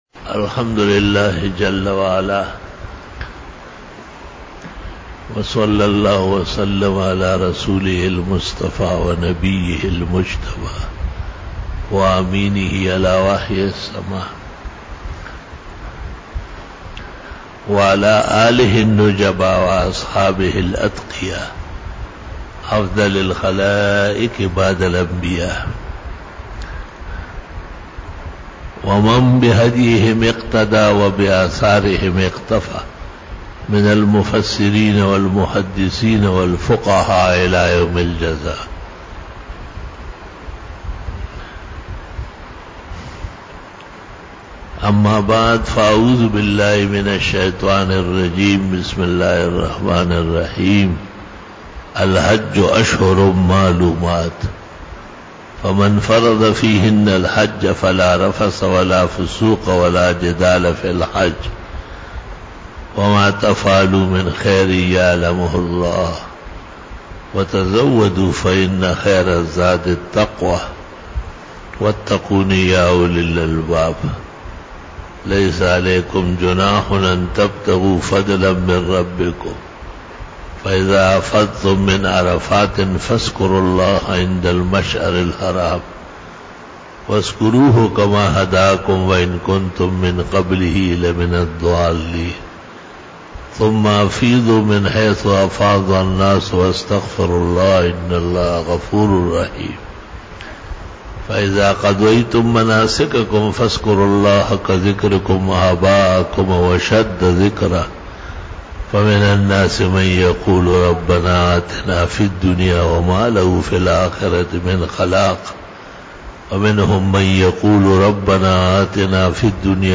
27 BAYAN E JUMA TUL MUBARAK (05 July 2019) (01 Zil Qaadah 1440H)